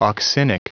Prononciation du mot auxinic en anglais (fichier audio)
Prononciation du mot : auxinic